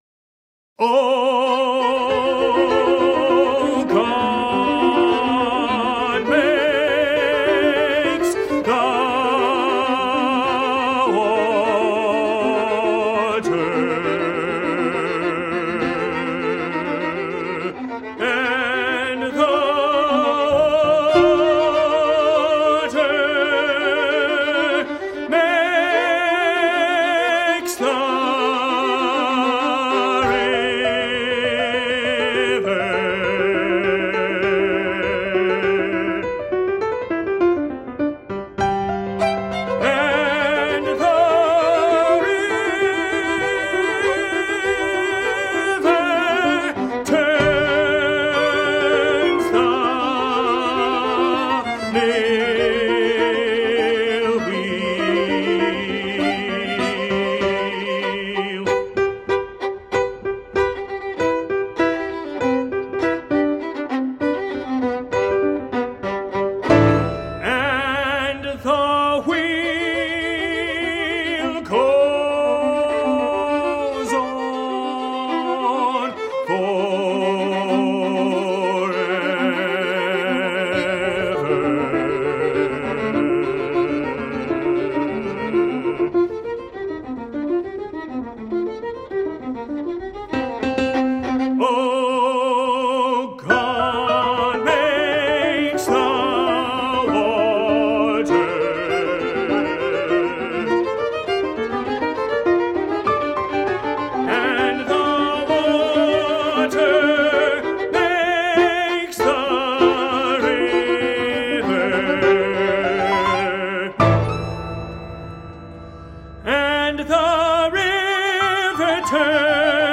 voice, violin and piano